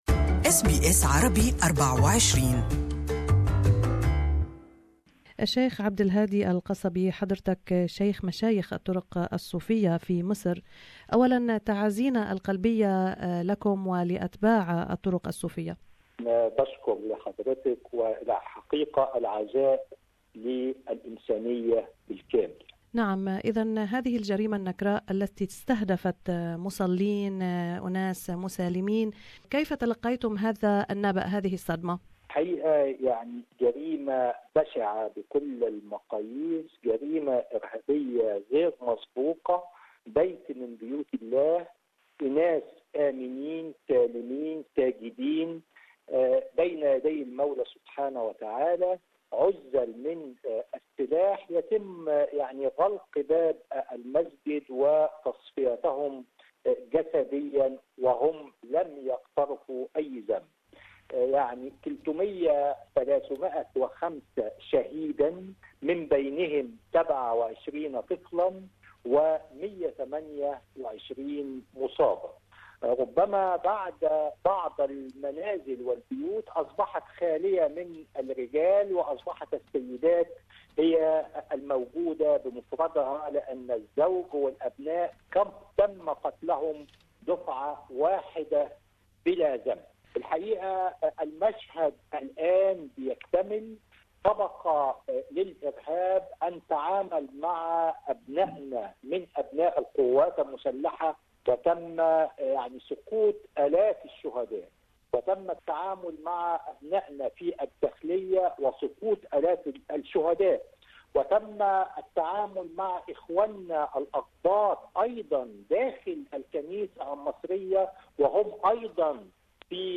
Sheikh Abdul Hadi Qasabi is the highest Sufi leader in Egypt. In this interview with SBS Arabic24 he accuses external forces of trying to destabilise Egypt.